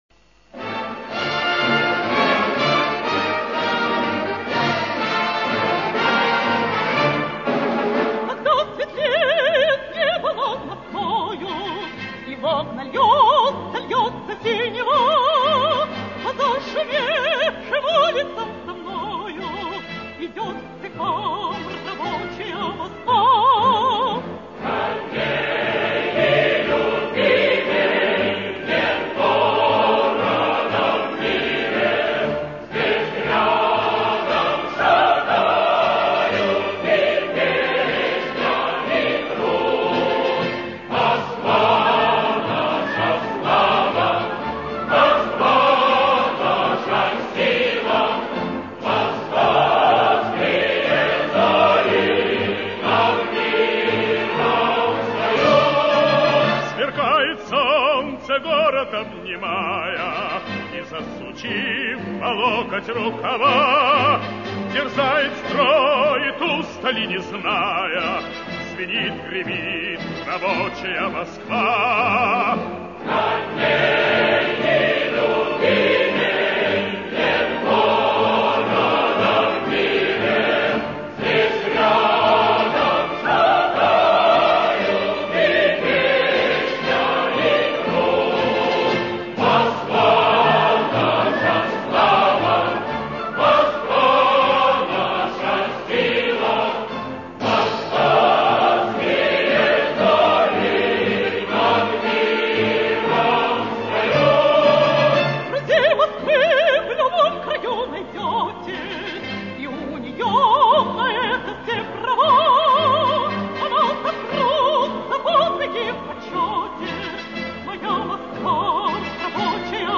Песня о столице советского и мирового пролетариата